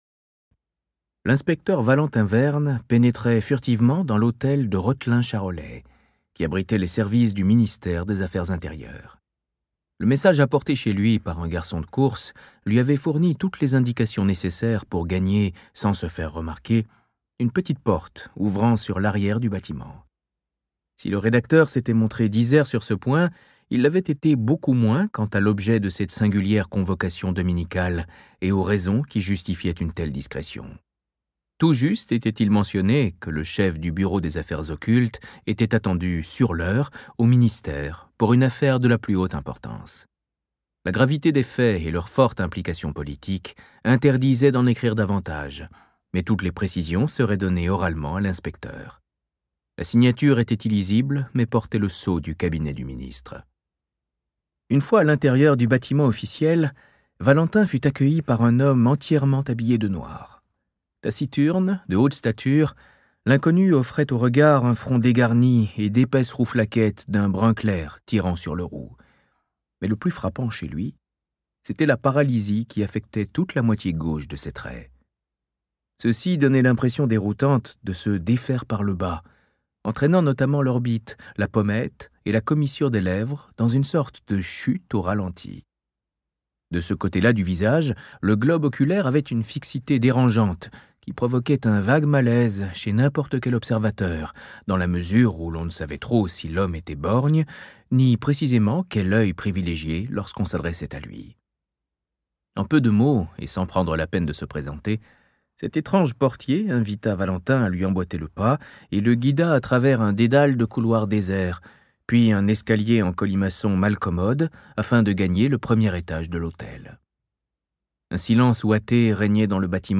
Extrait gratuit - Le Bureau des Affaires Occultes - Tome 4 : Le chant maléfique de Eric Fouassier